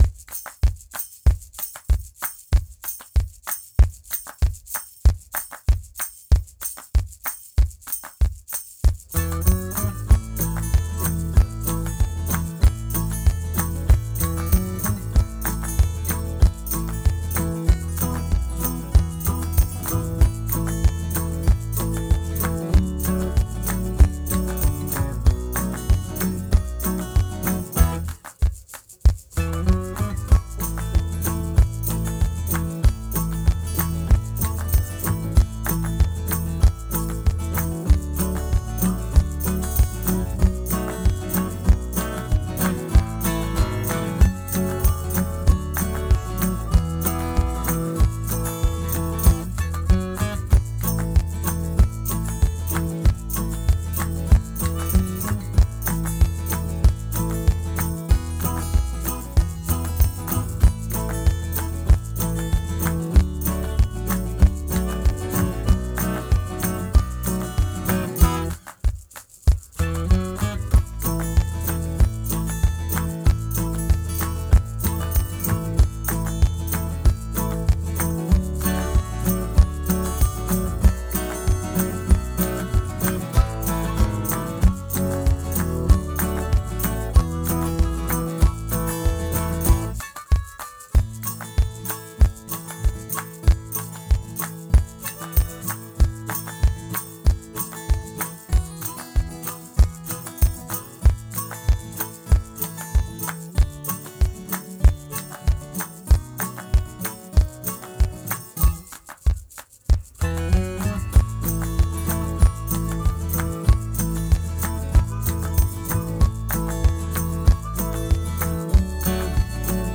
Thats-Me-instrumental-1.m4a